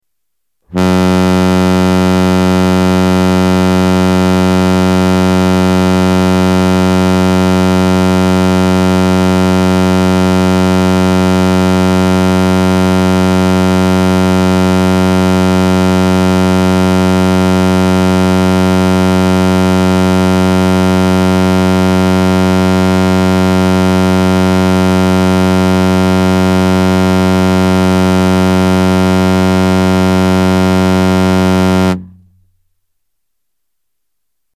• Frequency: 90 Hz
• Intensity @ 1m: 146 db(A)
• First piston type horn driven by AC 3 phase motor
The ZET-Horn sound is generated by a piston inside a cylinder driven by an AC 3-phase motor; the piston oscillates at certain frequencies making the air inside the sound projector vibrate in resonance to produce a clean sound audible over a wide range.
The ZET-Horn has a run-up time of less than 0.15 seconds which guarantees a pure and concentrated sound featuring optimal directional properties.
ZetHorn-90-AC.mp3